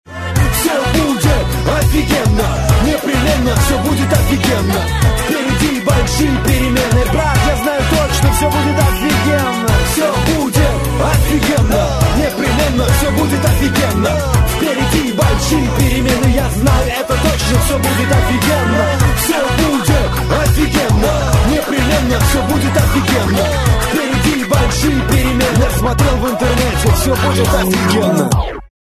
Главная » рингтоны на телефон » Рэп, Хип-Хоп, R'n'B